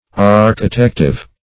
Search Result for " architective" : The Collaborative International Dictionary of English v.0.48: Architective \Ar`chi*tec"tive\, a. Used in building; proper for building.